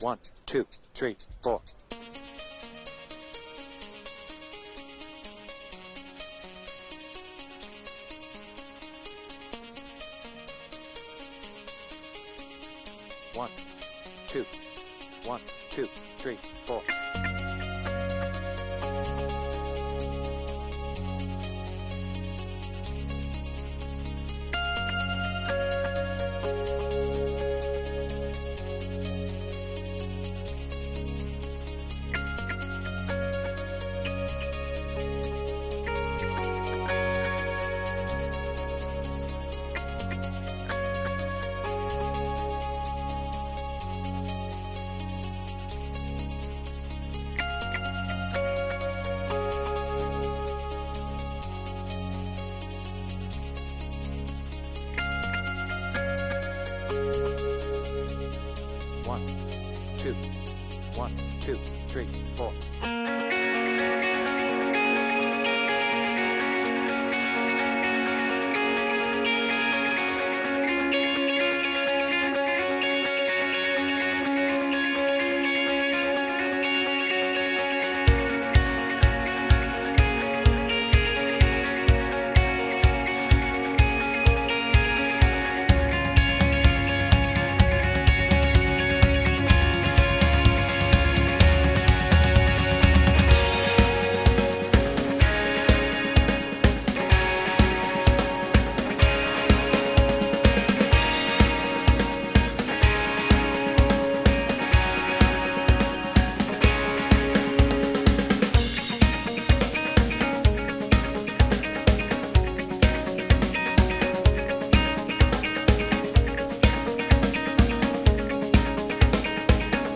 BPM : 126
Without vocals